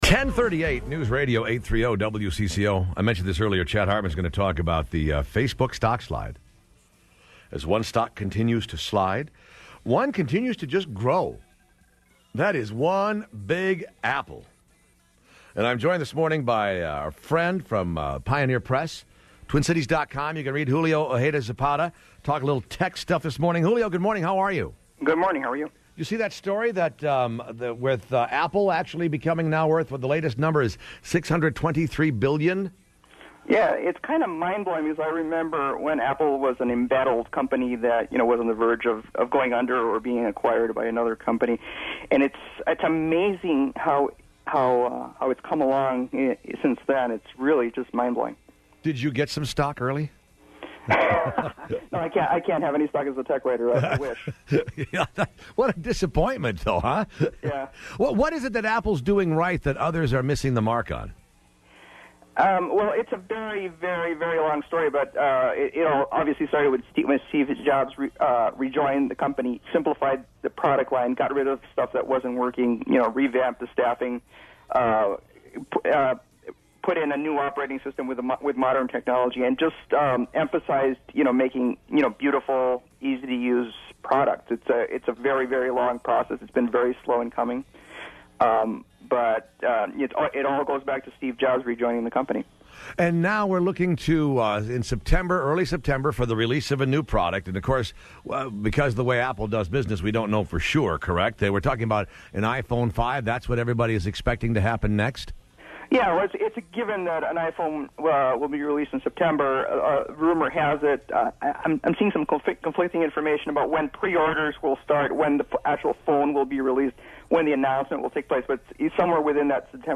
I talk iPhone on WCCO radio
We also talked classic-typewriter apps and accessories … and I even played typing-app sounds over the phone.